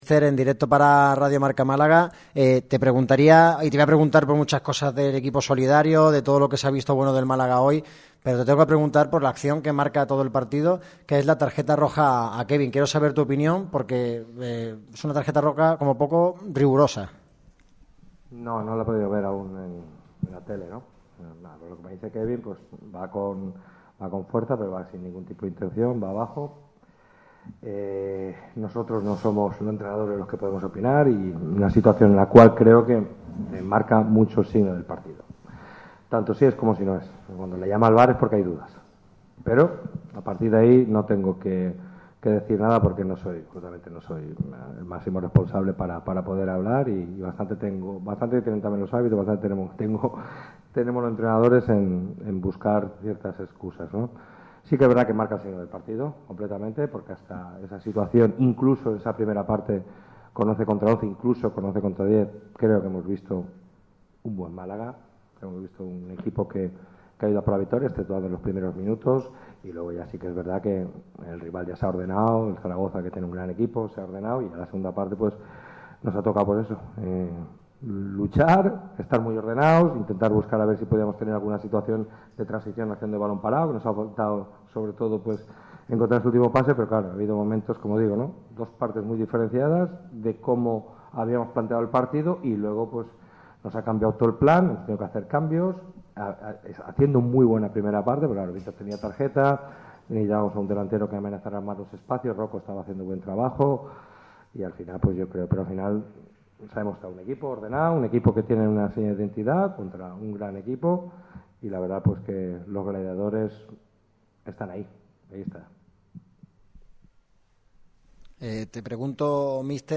El entrenador malaguista ha comparecido ante los medios tras la disputa del duelo que se ha resuelto con empate a cero en La Romareda ante el Real Zaragoza.